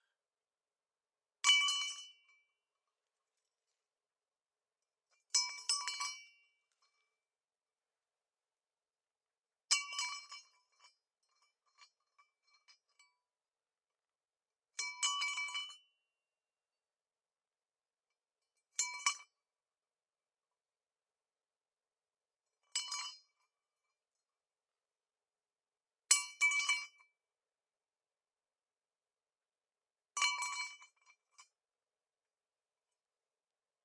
钢管落在混凝土上 " 钢，空心管落在混凝土上，8次冲击，400赫兹高通量
描述：在混凝土地板上放下铝管。因为它在室外我在400Hz下应用高通滤波器没有其他编辑录像机：放大H6与XY capsuelResolution：96/24
Tag: 混凝土 管道 金属 铁匠 金属 命中 冲击 弗利